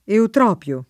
Eutropio [ eutr 0 p L o ]